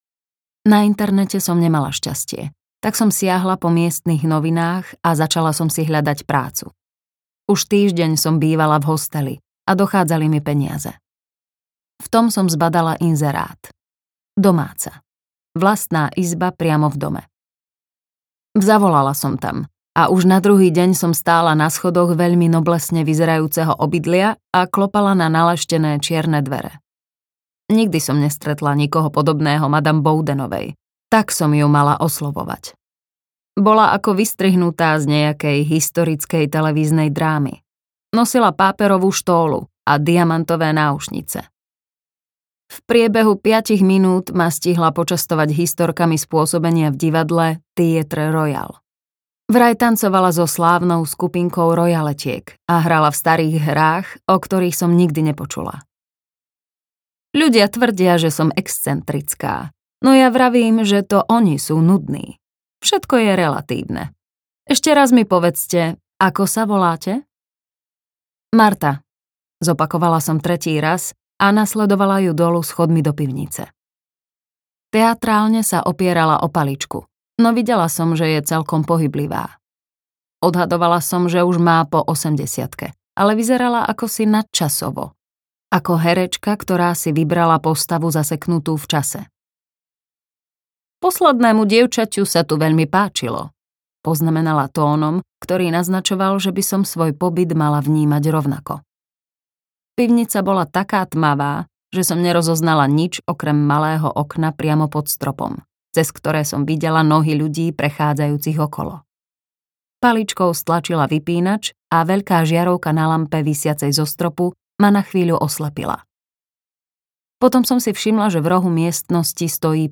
Stratené kníhkupectvo audiokniha
Ukázka z knihy